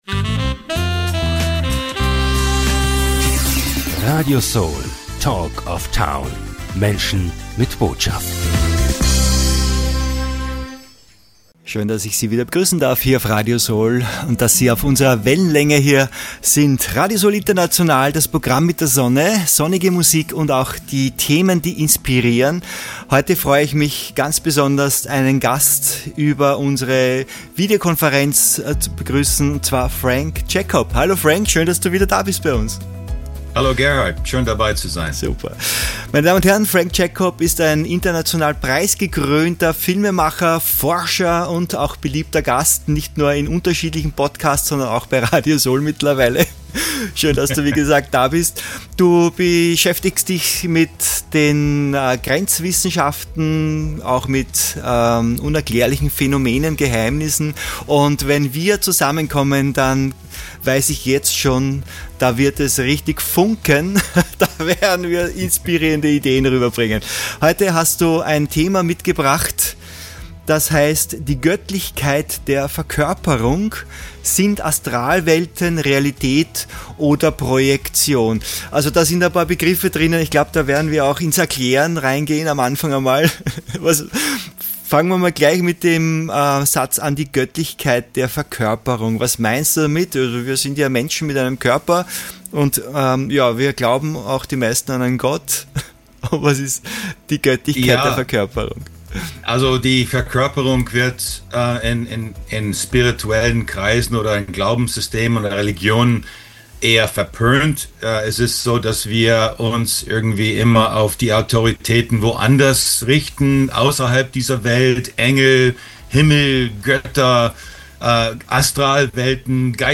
In diesem inspirierenden Interview spricht er über die Göttlichkeit der Verkörperung und geht der Frage nach, ob Astralwelten Realität oder Projektion sind – und ob es so etwas wie „böse“ Elemente im Universum gibt.